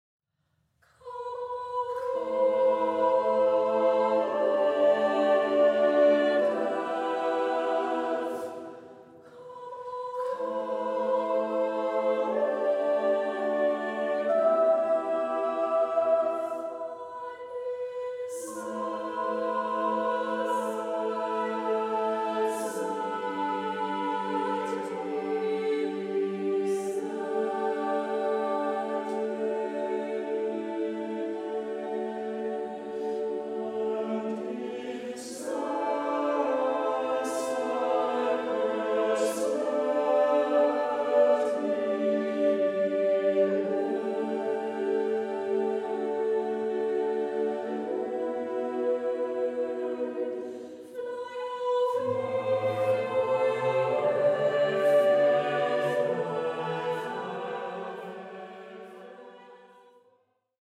MIXED CHORUS
A Cappella
SATB